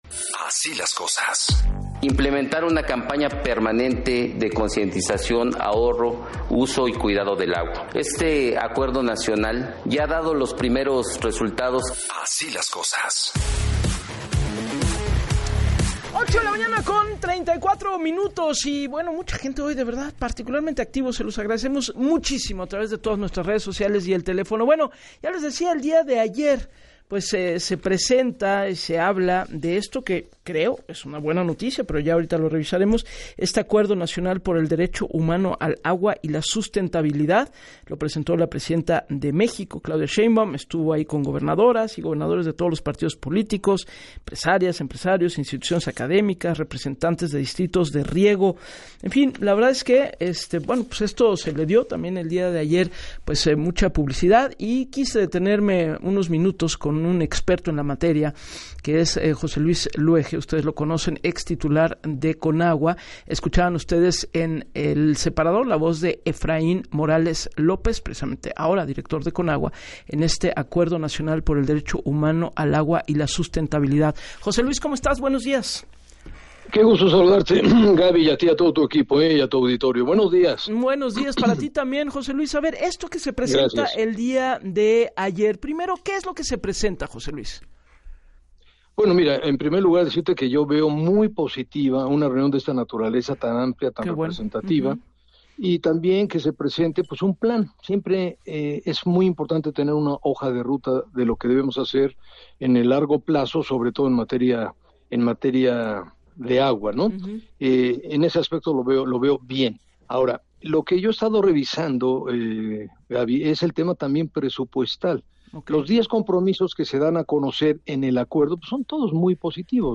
El exfuncionario puntualizó en entrevista para “Así las Cosas” con Gabriela Warkentin, que “los diez compromisos dados a conocer son muy positivos: saneamiento, ordenamiento de concesiones, mayor eficiencia, tratamiento al cien por ciento evitar contaminación de ríos y cuencas, es lo que todos estamos buscando y esperando”.